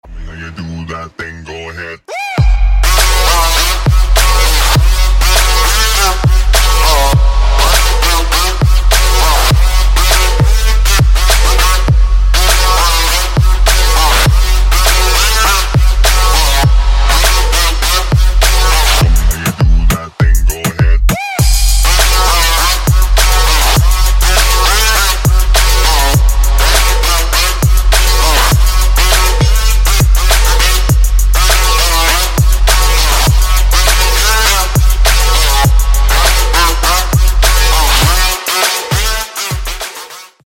• Качество: 192, Stereo
Electronic
Trap
club
Bass